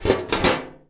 metal_tin_impacts_movement_rattle_01.wav